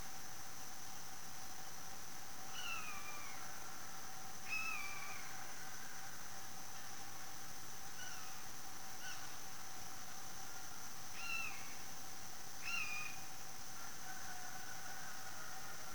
Miñato real
Milvus milvus
Canto